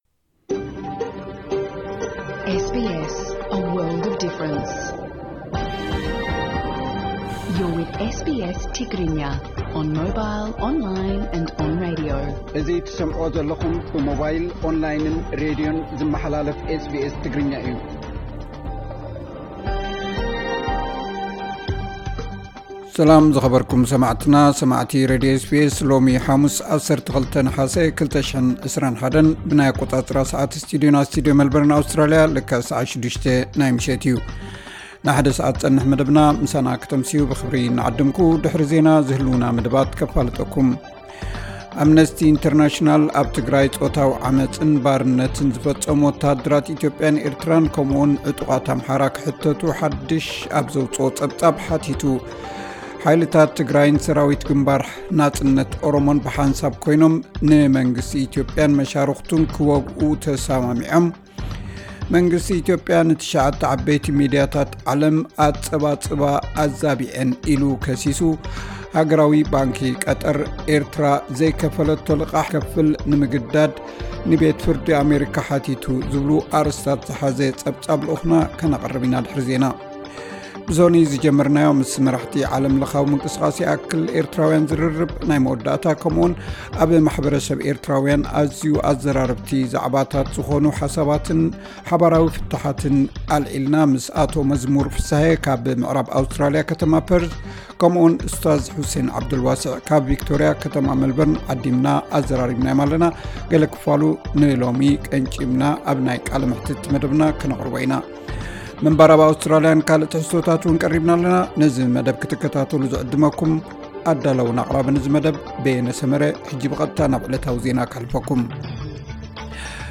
tigrinya_1208_news_0.mp3